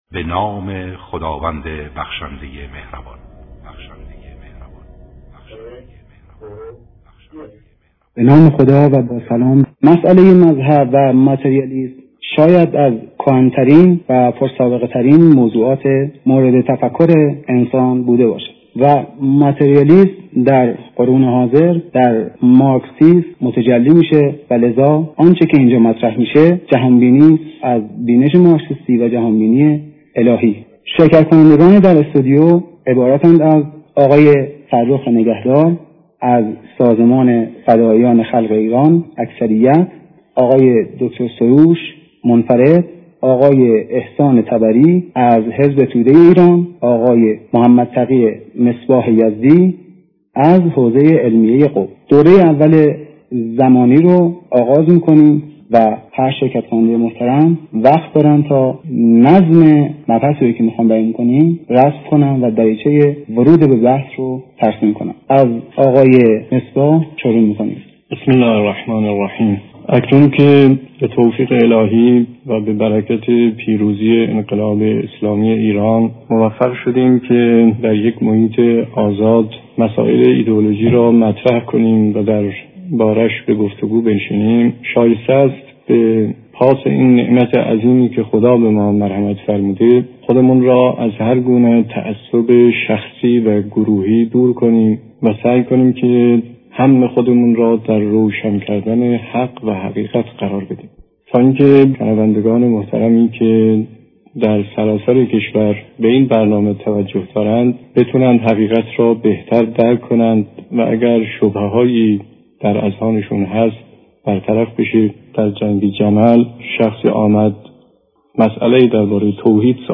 صوت فیلم خلاصه جامع از مناظره بحث آزاد مصباح و طبری.mp3